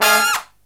FALL HIT13-L.wav